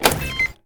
liftbutton.ogg